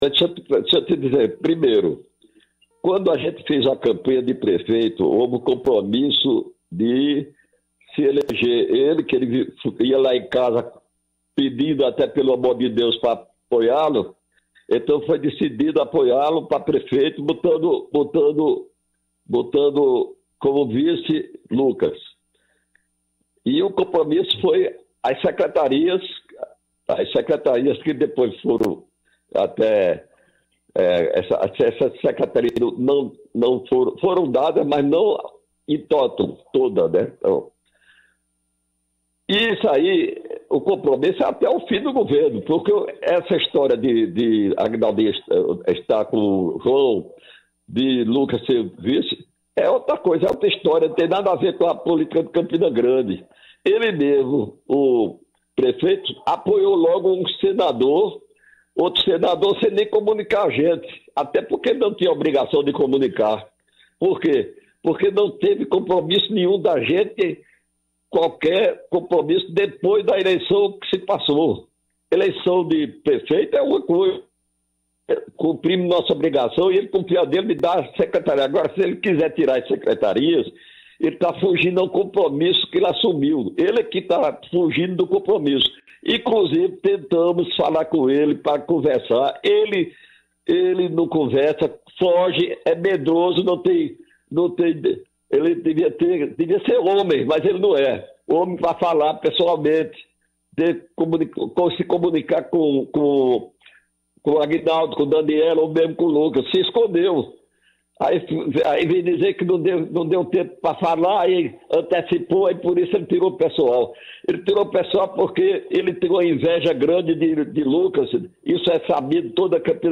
Em entrevista, Enivaldo desabafou e disse que ficou “revoltado” com a reação do prefeito.